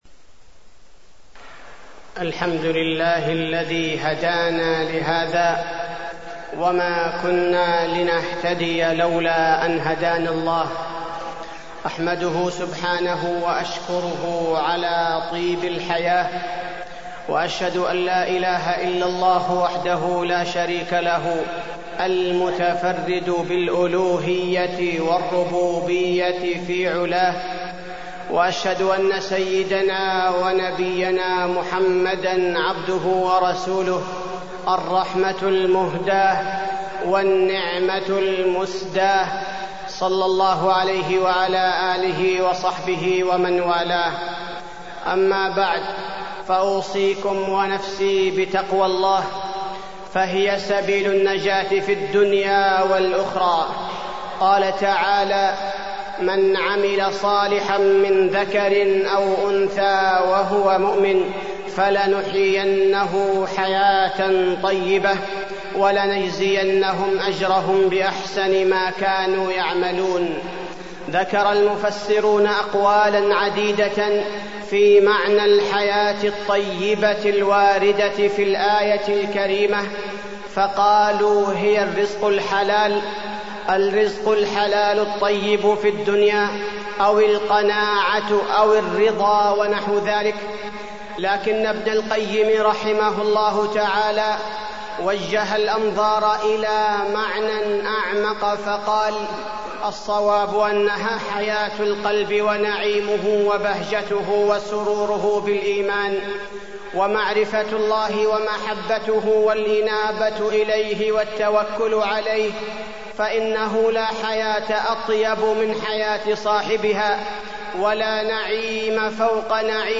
تاريخ النشر ٧ ذو القعدة ١٤٢٣ هـ المكان: المسجد النبوي الشيخ: فضيلة الشيخ عبدالباري الثبيتي فضيلة الشيخ عبدالباري الثبيتي الحياة الطيبة